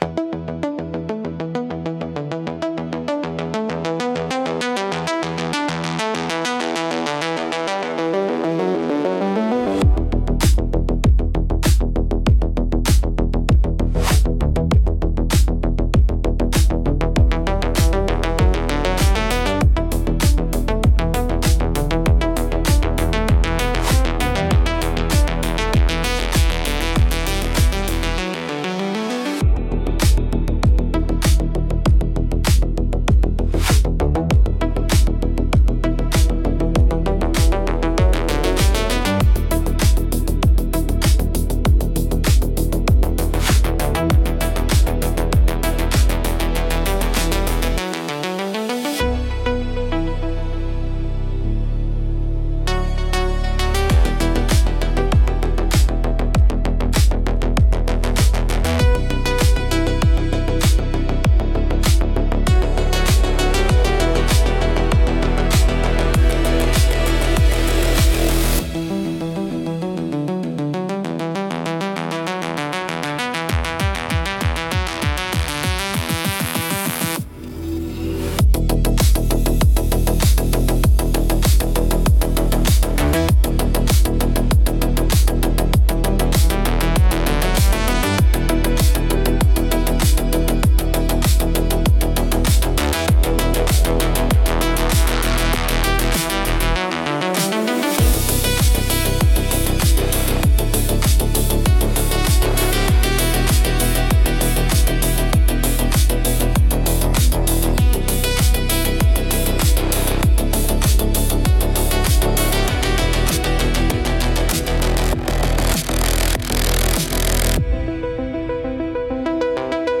porsch sound clip.mp3